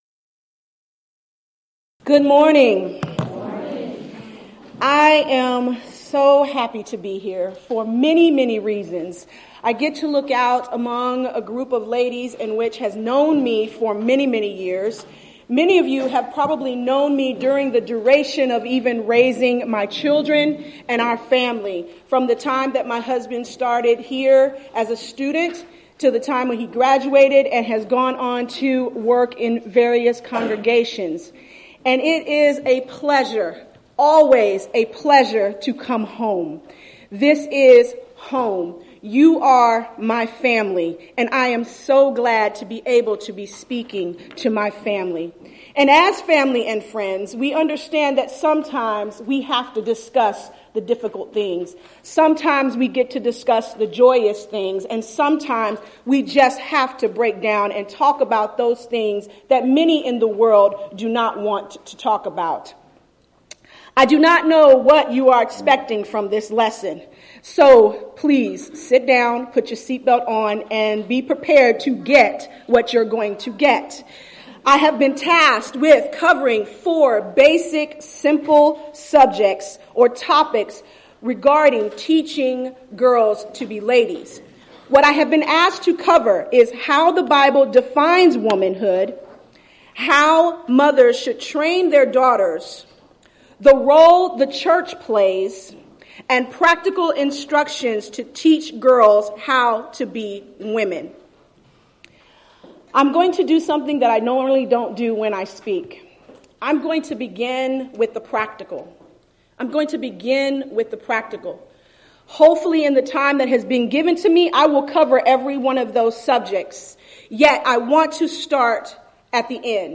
Event: 3rd Annual Southwest Spritual Growth Workshop
Ladies Sessions